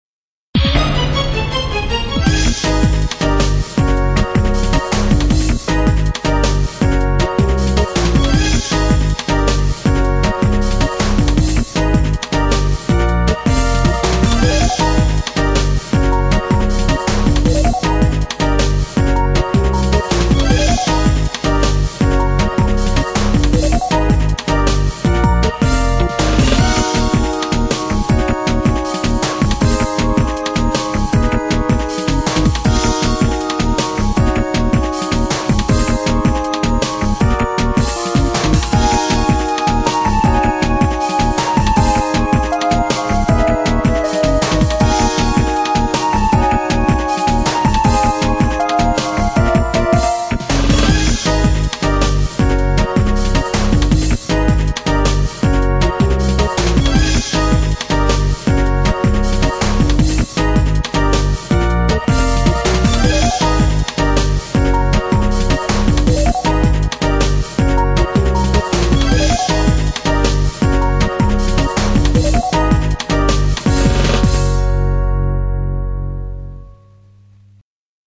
音質下げてます、ご了承下さい。
イントロのアタックにショック感、後に危機が訪れる、といった流れ。